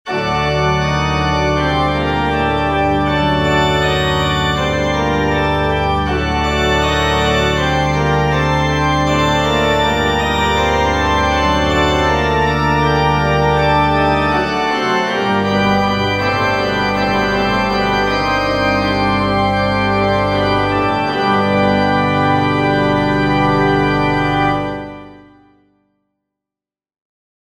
Orgelpunkt (französisch: Point d'orgue) oder anhaltende Cadenz [Kadenz] ist eigentlich eine Aufhaltung der Finalkadenz in Fugen oder fugenartigen Sätzen, die dadurch entsteht, dass auf der dem Schlussfall vorhergehenden Dominante der Grundstimme einige Takte hindurch entweder ein Teil des Hauptsatzes in den übrigen Stimmen wiederholt und nachgeahmt oder die vorhergehende Melodie in verschiedenen harmonischen Verwicklungen fortgesetzt wird, wie bei [folgendem Notenbeispiel], wo man zugleich sieht, dass ein solcher Orgelpunkt auch noch auf der Tonika der Grundstimme fortgesetzt werden kann.